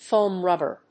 アクセントfóam rúbber
音節fòam rúbber